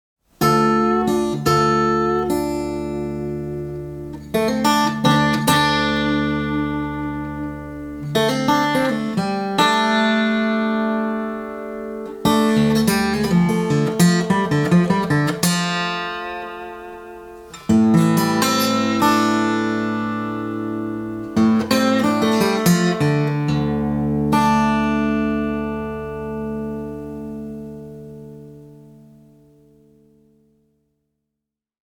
Véritable anthologie du blues acoustique